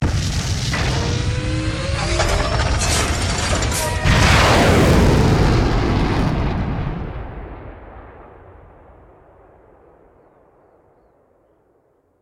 launch.ogg